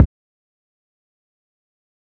Kick (Oh My).wav